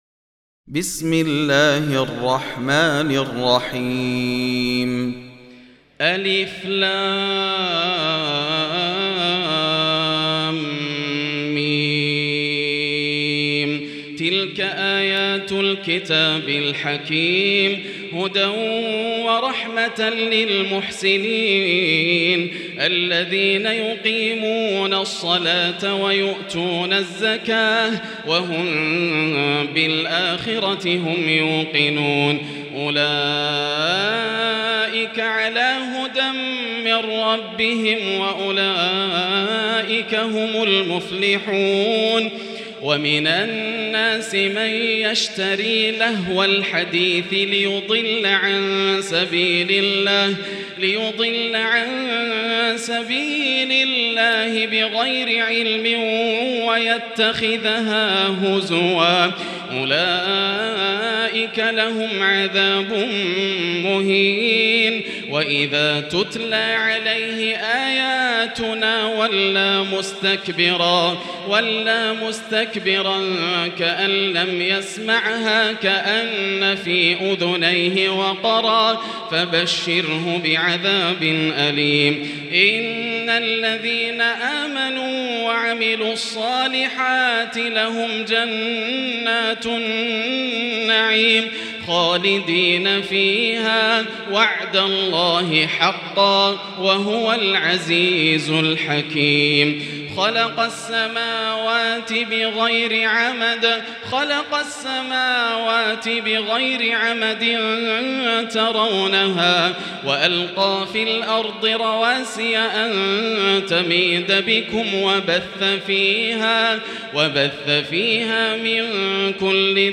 المكان: المسجد الحرام الشيخ: فضيلة الشيخ ياسر الدوسري فضيلة الشيخ ياسر الدوسري لقمان